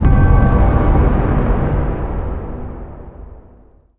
youlose.wav